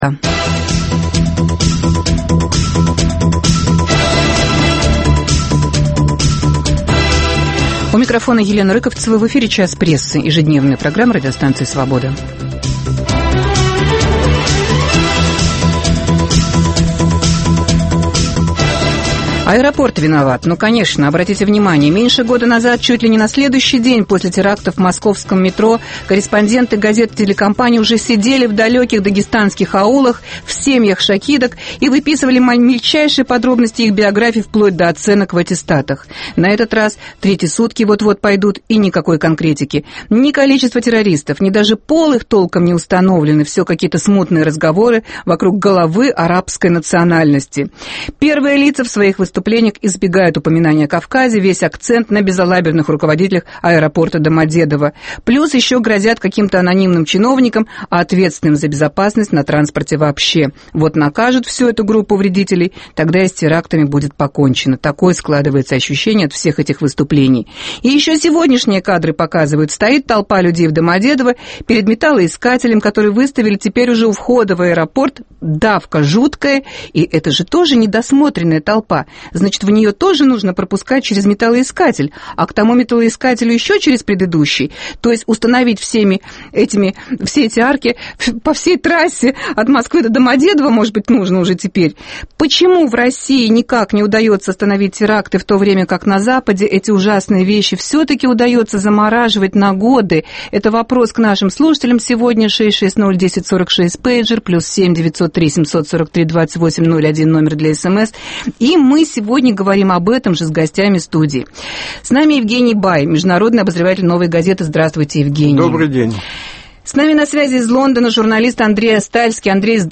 Почему одним удается "заморозить" теракты, а другим нет? Чем отличается отношение к террористической угрозе в России от того, что существует в западных странах? В студии